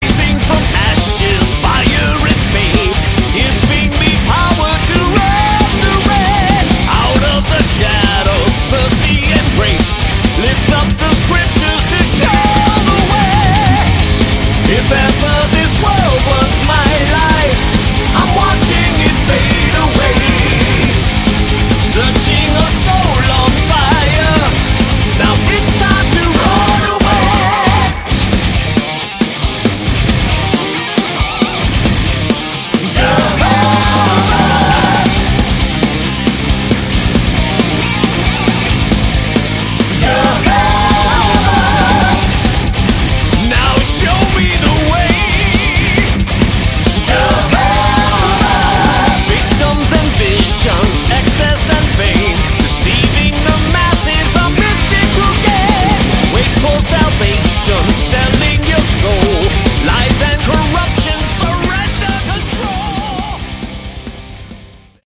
Category: Melodic Metal
lead guitar
lead vocals
bass
drums